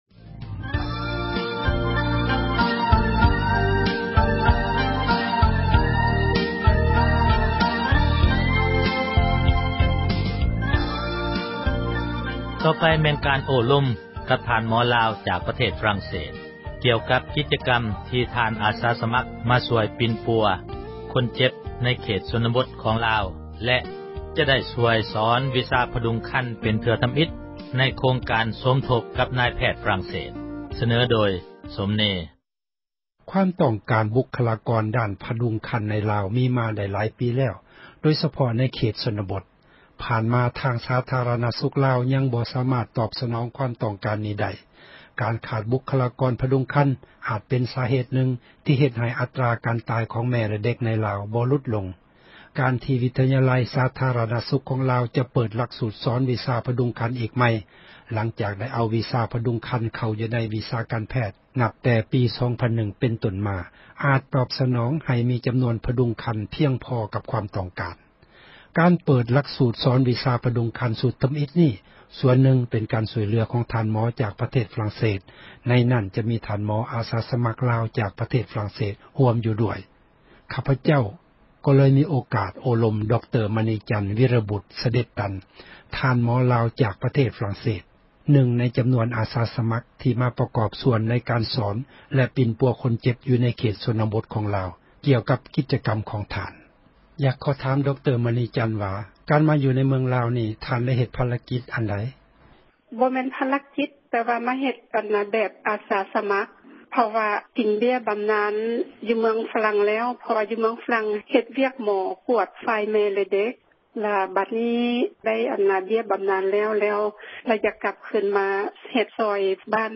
ໂອ້ລົມກັບທ່ານໝໍລາວ ຈາກຝຣັ່ງເສສ (ຕໍ່)